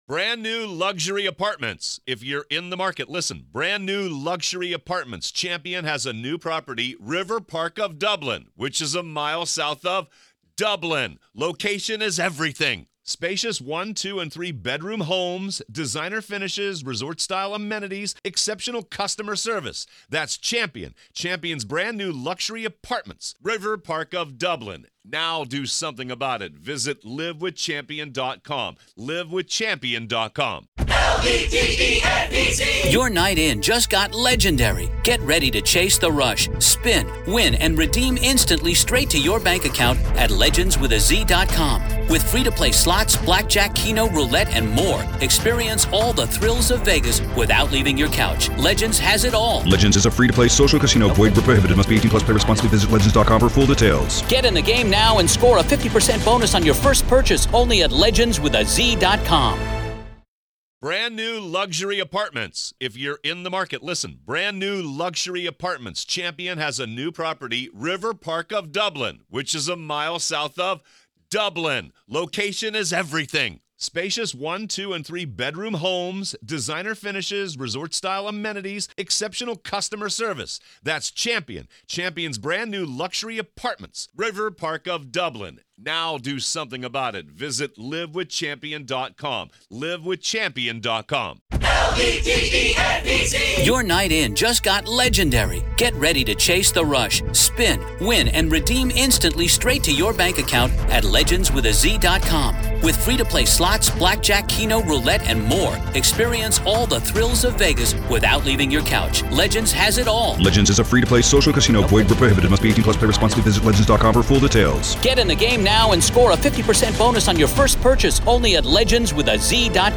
Raw Court Audio-MA v. Karen Read — Jury Questioning with Judge Beverly Cannone